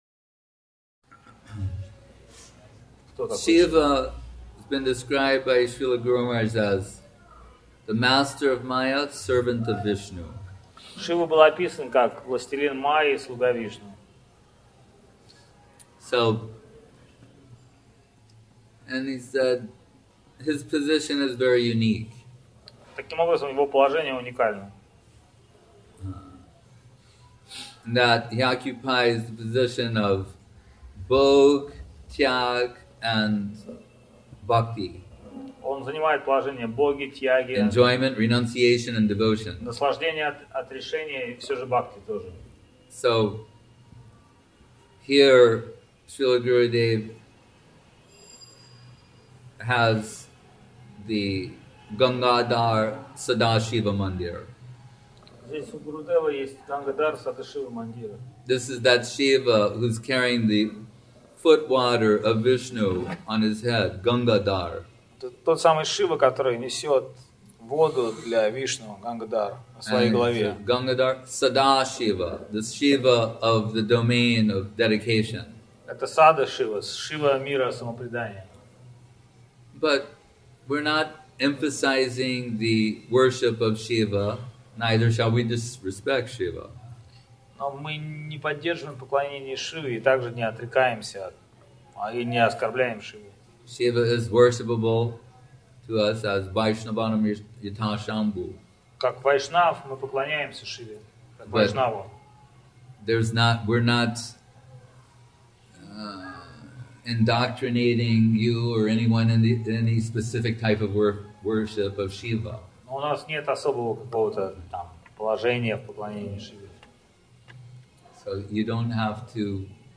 Место: ШЧСМатх Навадвип